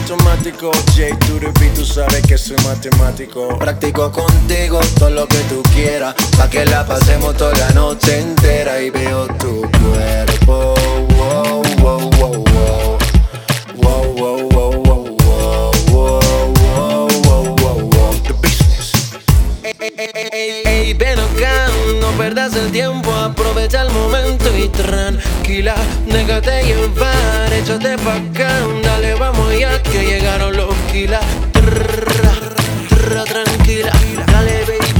Жанр: Латиноамериканская музыка
# Urbano latino